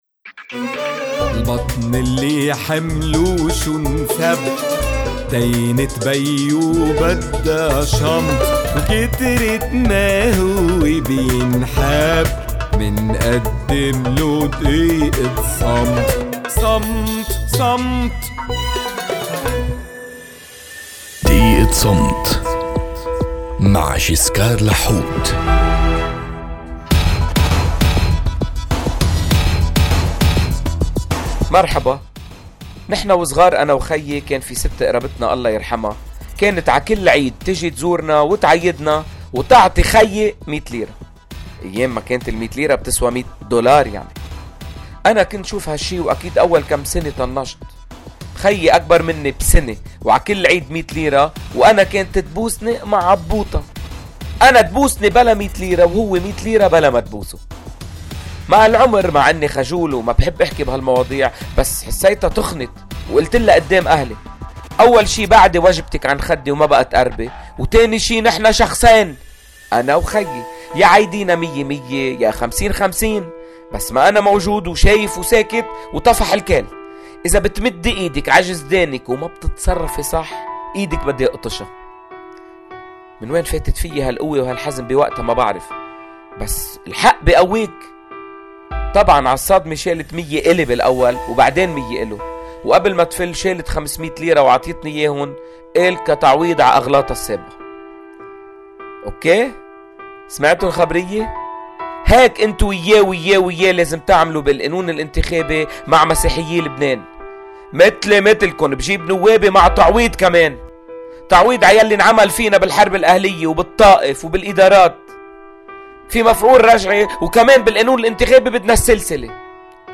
على إذاعة “صوت المدى”: